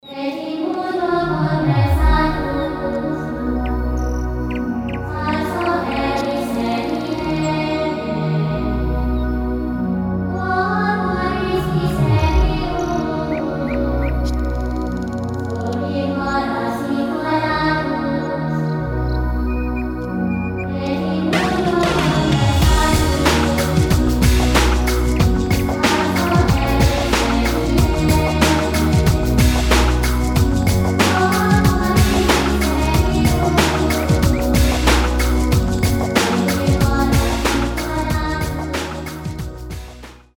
нарастающие
релакс
Пение птиц
Mashup
New Age